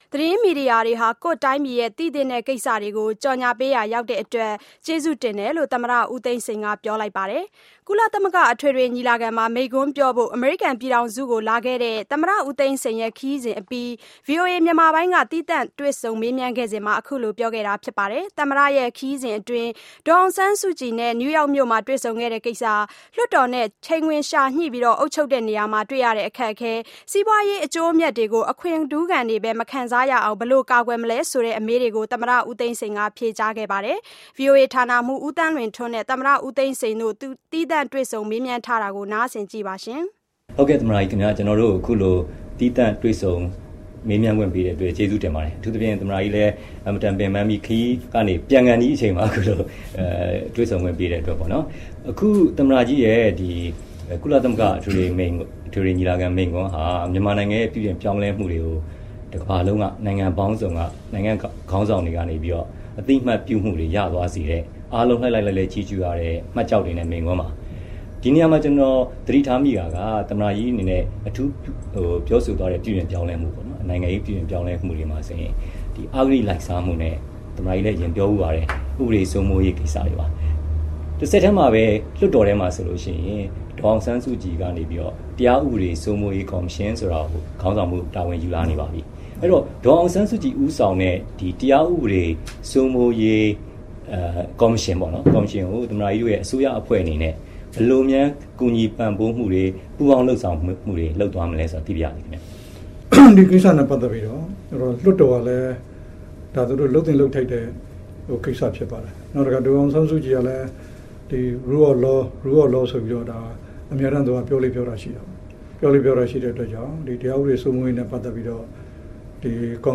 VOA Interview with President Thein Sein